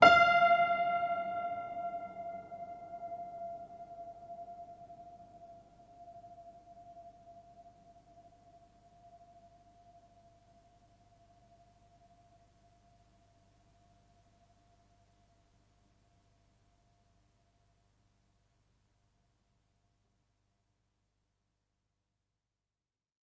它的持续声音非常好，可以用于分层作曲，特别是部分或反向演奏。
Tag: 恐怖 失谐 钢琴 维持踏板